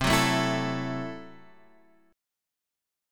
C6b5 Chord